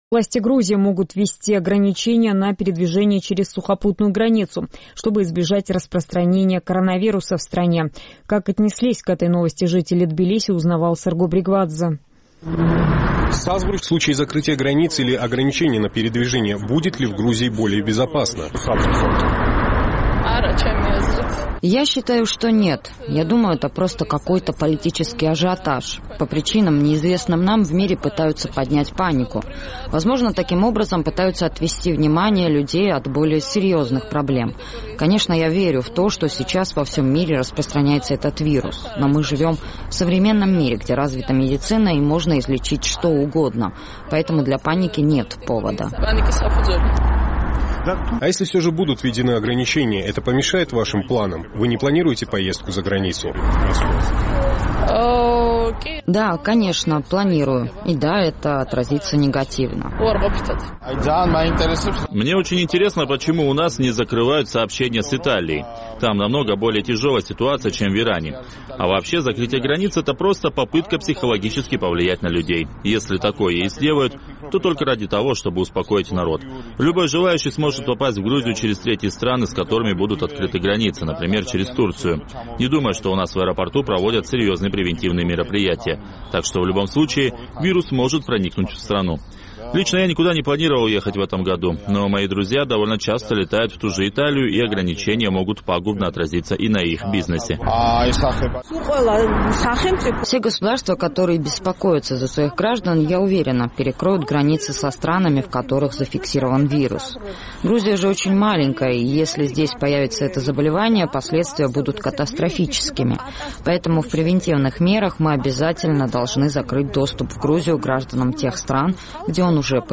Власти Грузии могут ввести ограничения на передвижение через сухопутную границу, чтобы избежать распространения коронавируса в стране. Наш тбилисский корреспондент узнавал, как отнеслись к этой новости жители грузинской столицы.